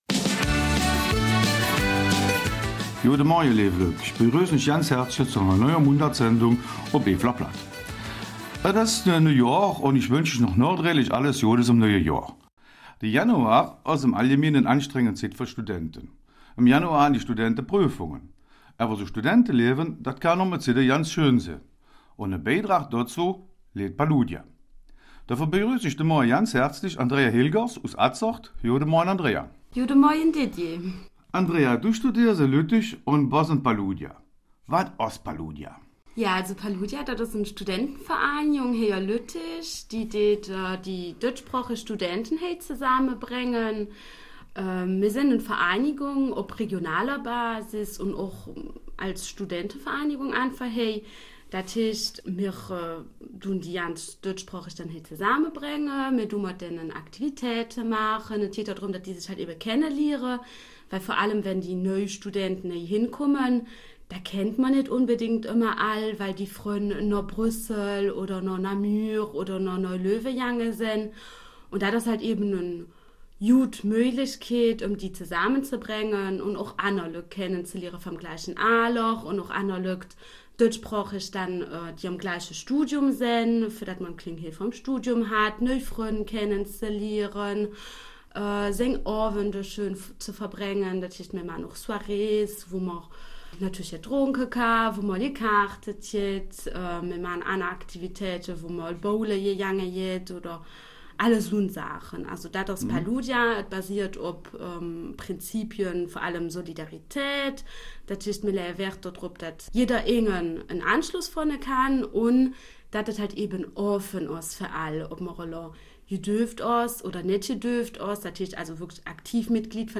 Eifeler Mundart: Studentenverbindung Paludia 13.